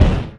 Energy Hit 04.wav